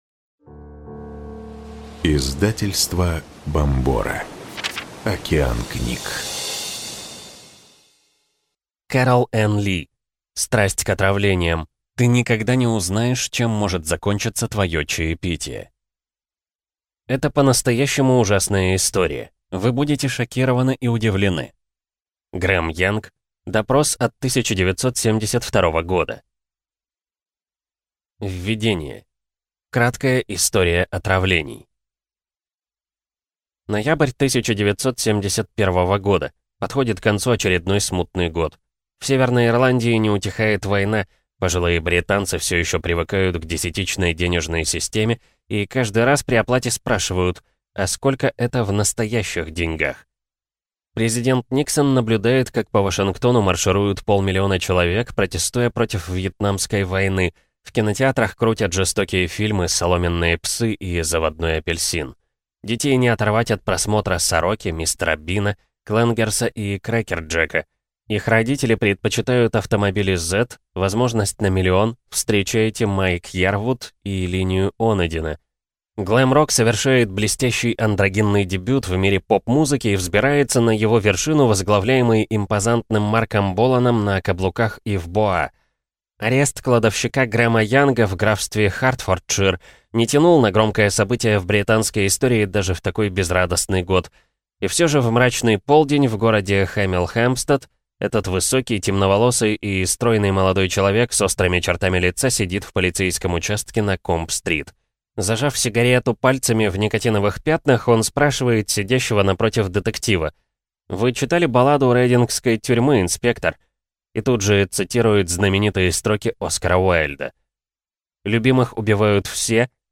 Аудиокнига Страсть к отравлениям. Ты никогда не узнаешь, чем может закончиться твое чаепитие | Библиотека аудиокниг